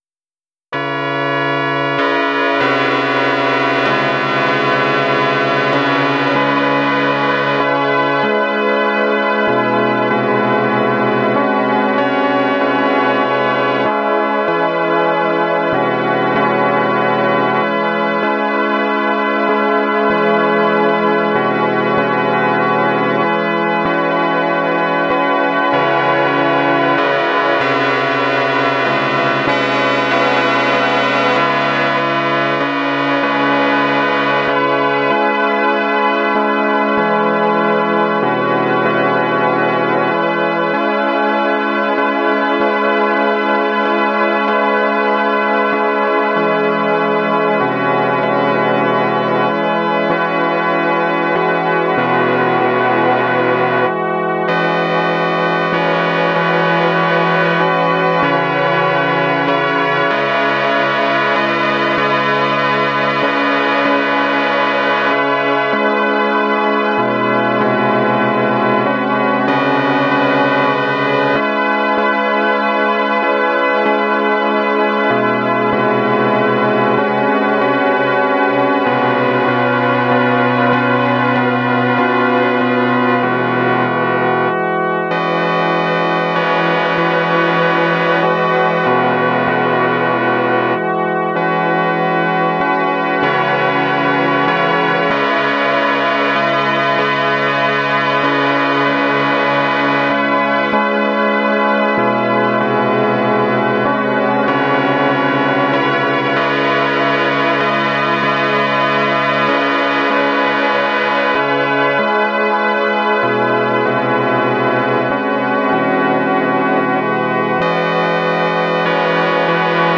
I modified sound to use slightly different envelopes for every partial, to sharpen the attack and to have the sound gradually decrease in amplitude over time. From the sound, I generated a dissonance curve and from that, generated a 12-tone just scale.
I slowed the result down, added legato and mapped it to my derived scale. The overlapping tails of notes ends up obscuring the brass sound and makes it sound more like a pipe organ or bells.